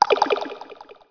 gate_open.wav